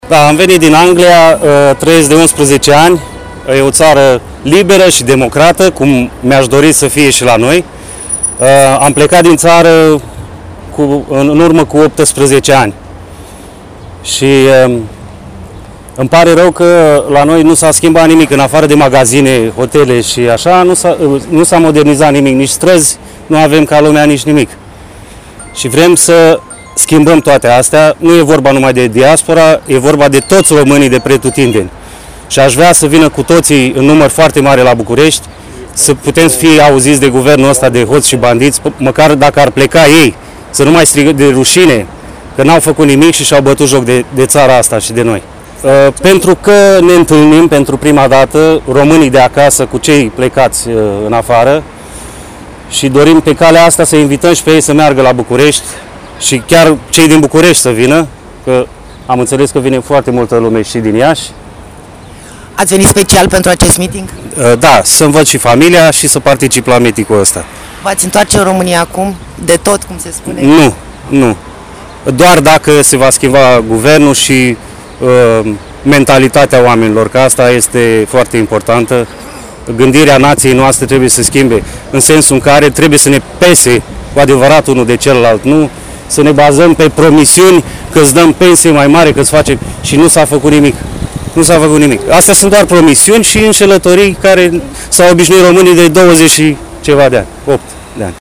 ” Nu s-a schimbat nimic în țară”, povește cu necaz bărbatul.
10-august-Anglia-site.mp3